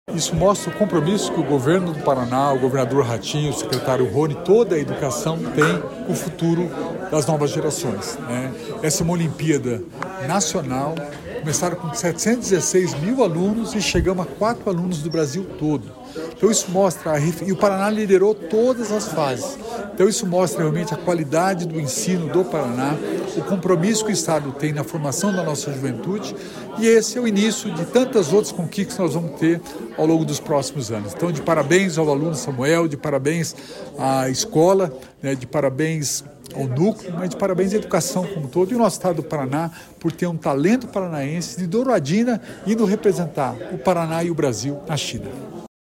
Sonora do secretário da Inovação e Inteligência Artificial, Alex Canziani, sobre estudante da rede estadual que participará da Olimpíada Internacional de Inteligência Artificial | Governo do Estado do Paraná